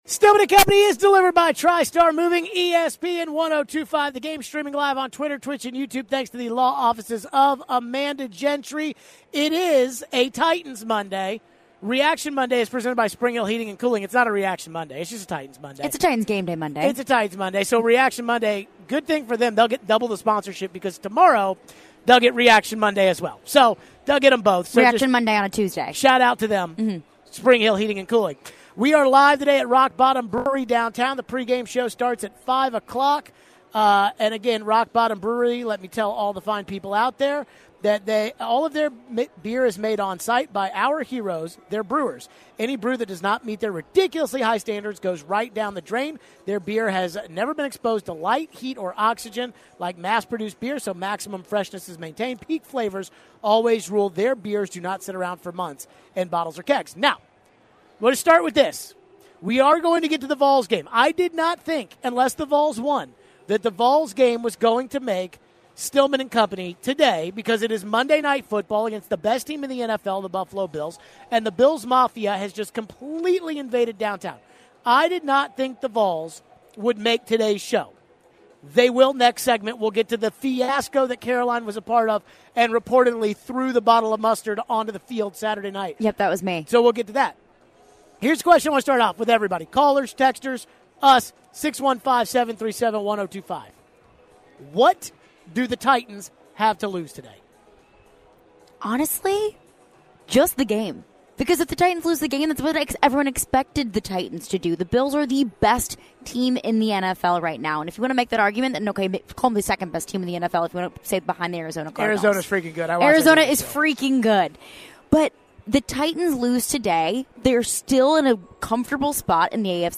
We take your phones on the Vols situation.